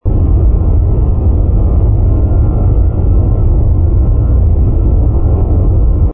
rumble_battleship.wav